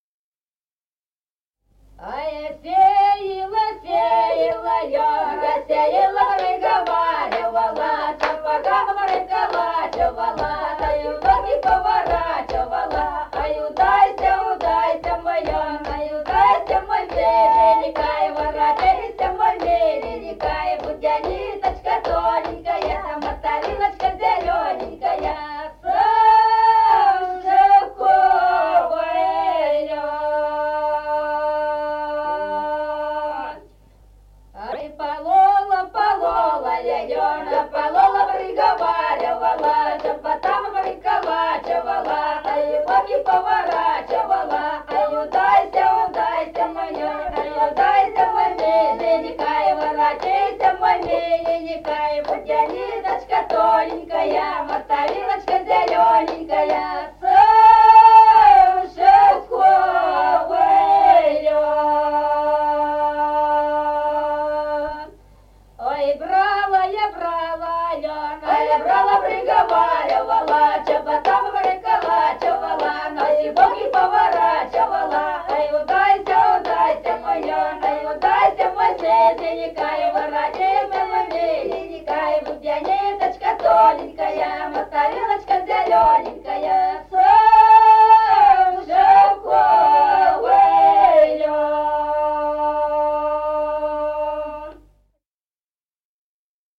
Народные песни Стародубского района «А я сеяла, сеяла лён», карагодная, игровая.
1953 г., с. Остроглядово.